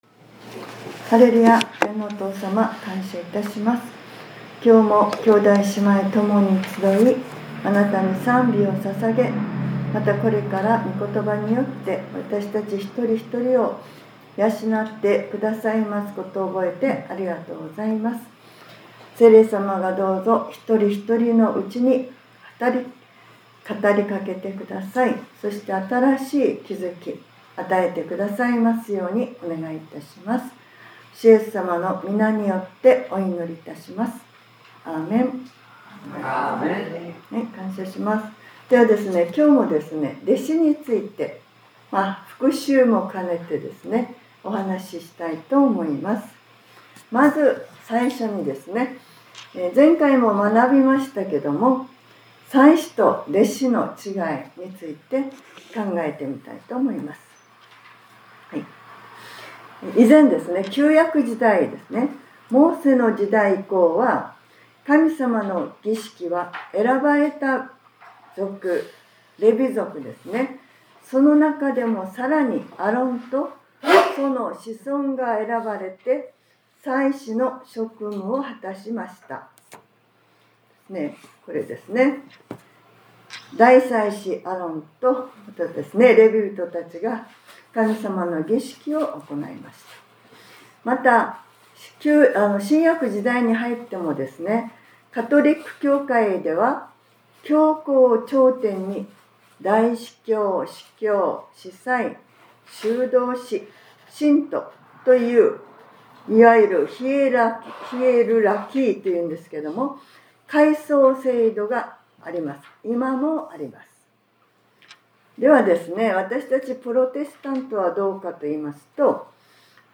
2025年11月23日（日）礼拝説教『 キリストの弟子- ２ 』